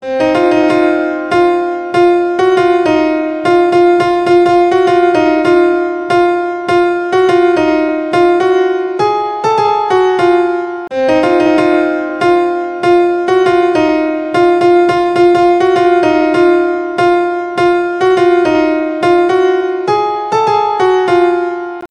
Categories: Piano